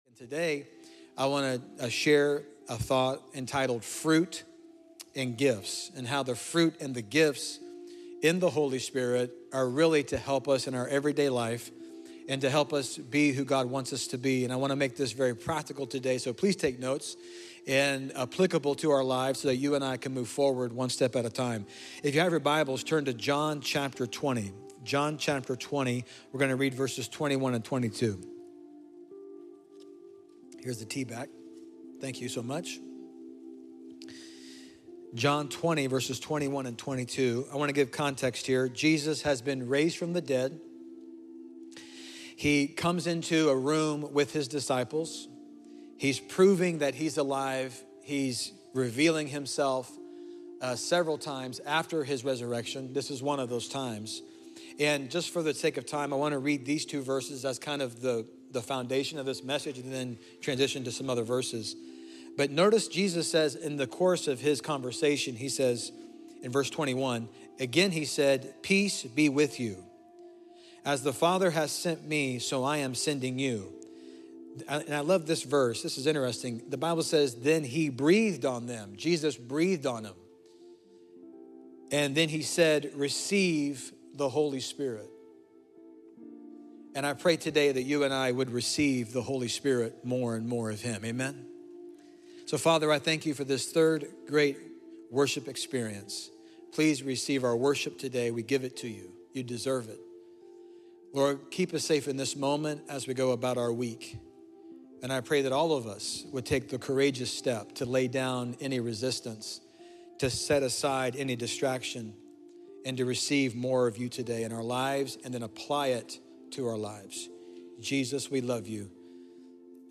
Fruit & Gifts by City Church For All Nations: Latest Sermons